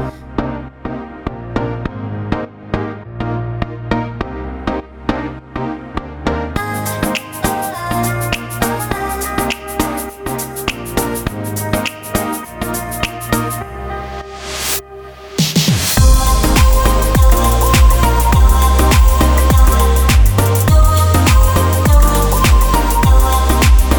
For Solo Male Pop (2010s) 3:42 Buy £1.50